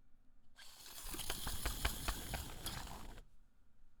• measuring tape 1.wav
captured with Sterling ST66